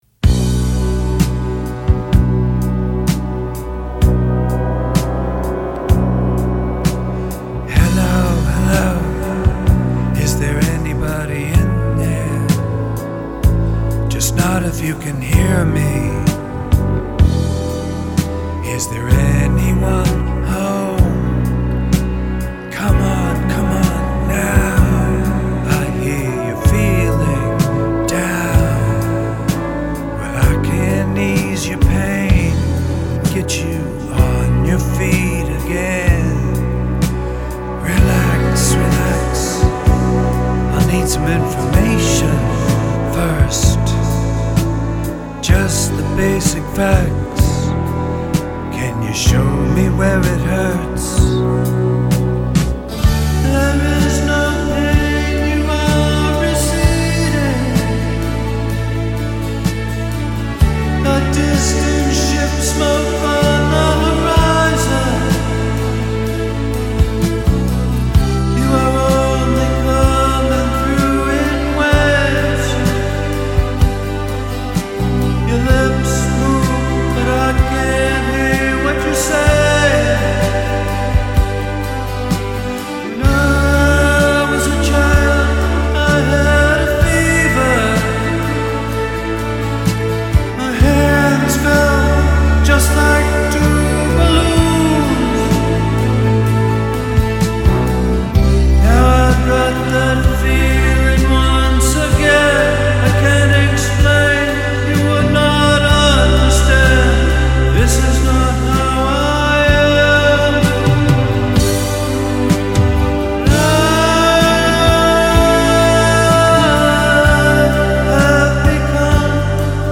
- All voices are me